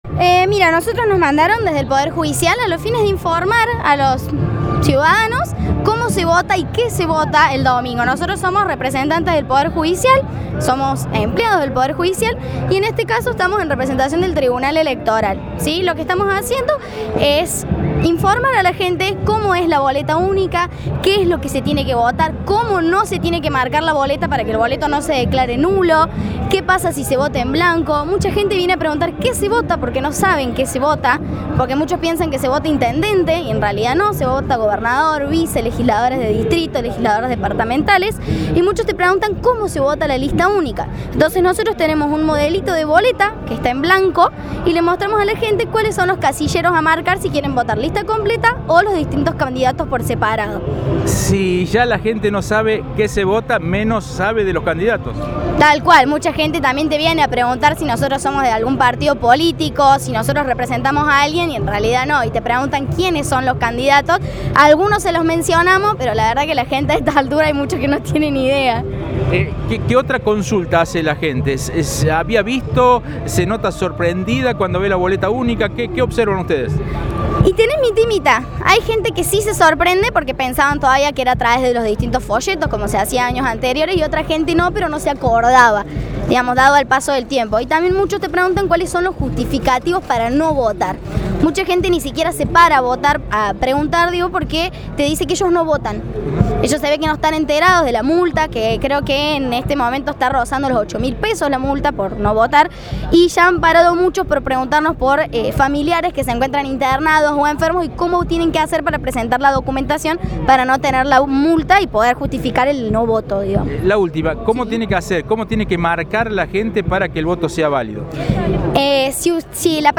Un equipo de trabajadores de la Justicia Electoral de Córdoba estuvieron este jueves en la plaza San Martín, asesorando e informando a los ciudadanos con respecto al uso de la Boleta única que se utilizará en las elecciones provinciales de este domingo en la provincia.